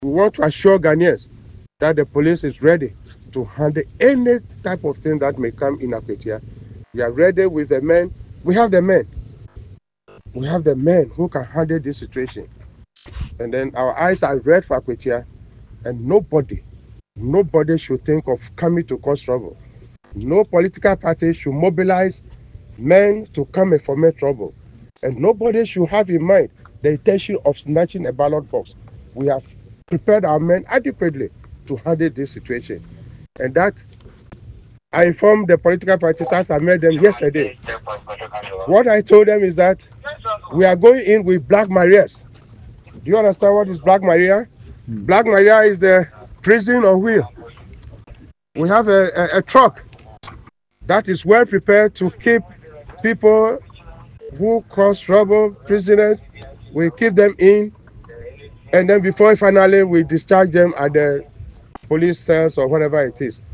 Speaking to recruits at the Police Training School at Kyerematen in the Eastern Region, IGP Yohonu stated, “During the Akwatia by-election, if you come with arms, we (Police) will deal with you with arms.